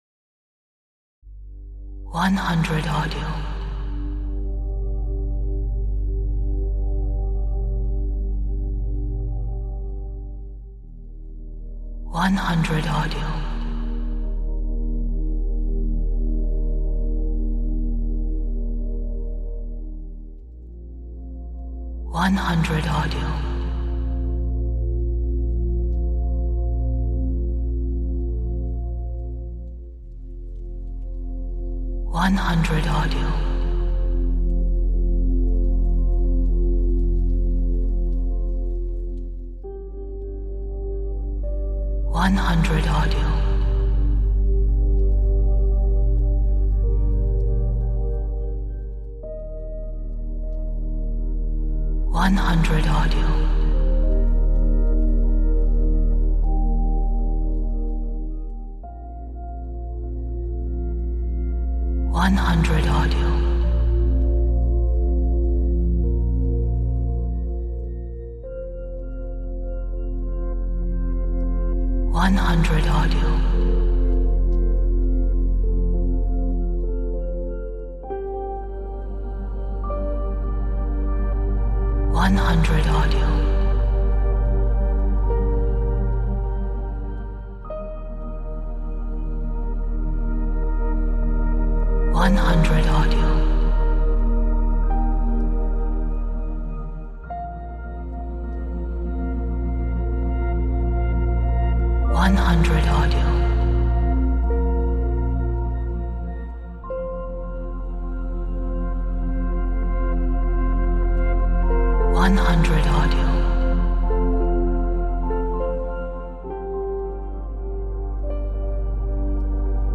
Relaxing, atmospheric meditation track!